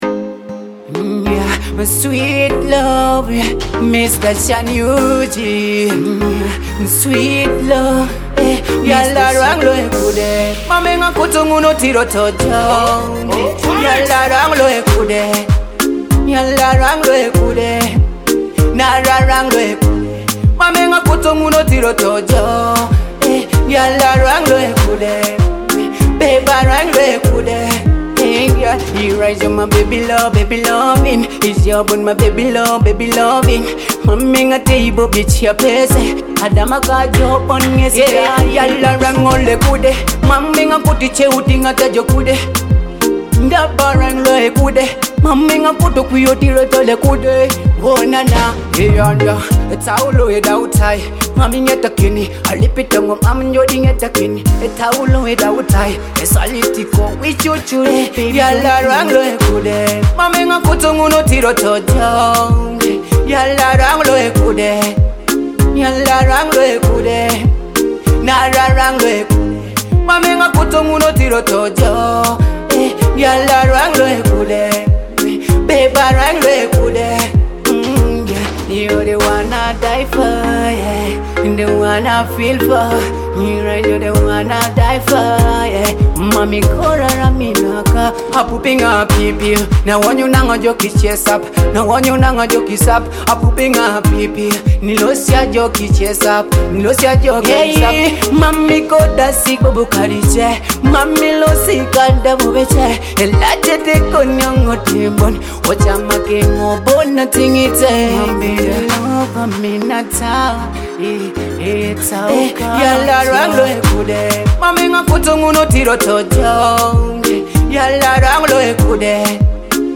Stream or download this catchy Afrobeat-Dancehall track now.
With catchy hooks, vibrant beats, and relatable lyrics